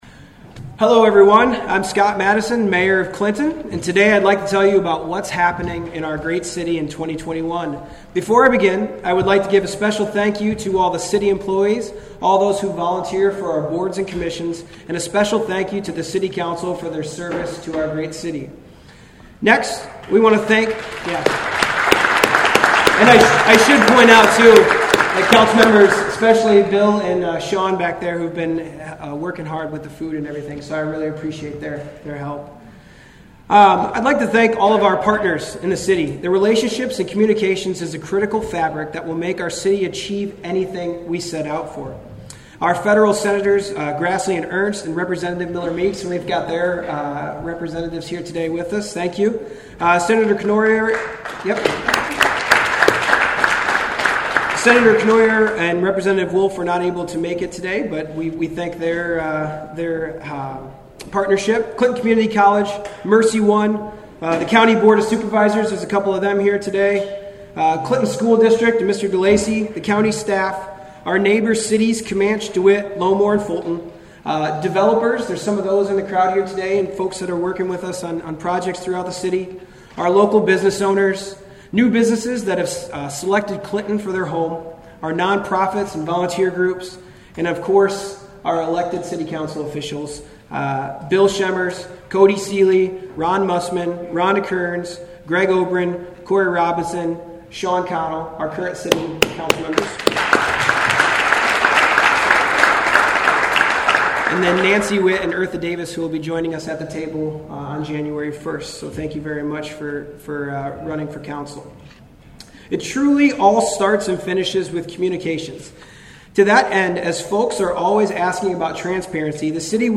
Clinton Mayor Scott Maddasion Presents State Of The City Address
Mayor-Scott-SateOfTheCity-11-23.mp3